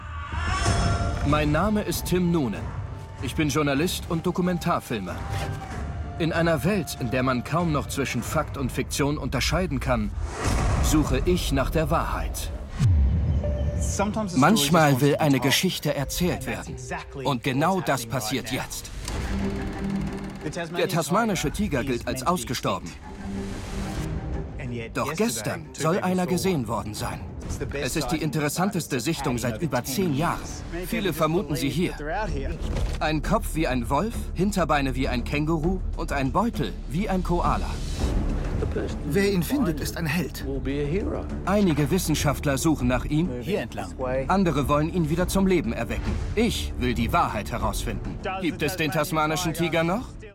sehr variabel, dunkel, sonor, souverän, markant
Mittel minus (25-45)
Norddeutsch
Doku